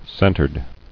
[cen·tered]